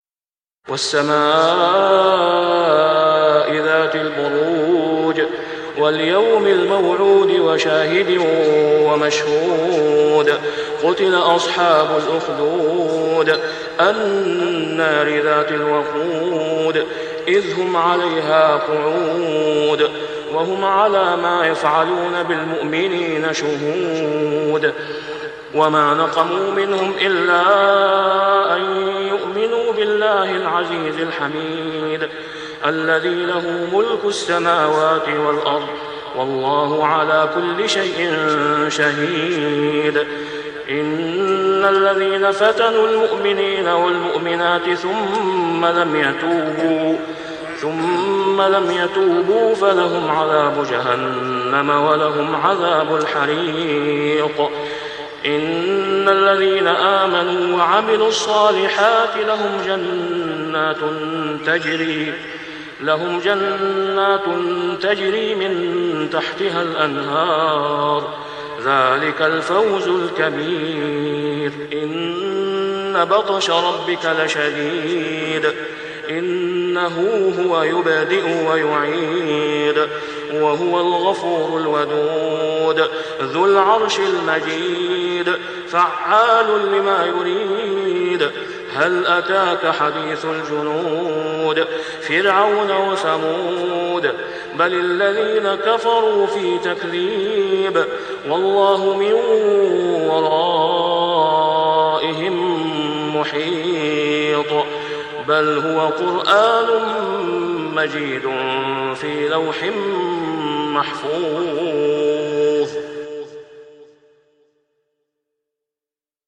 ( صلاة العشاء و العام غير معروف ) | سورة البروج كاملة > 1422 🕋 > الفروض - تلاوات الحرمين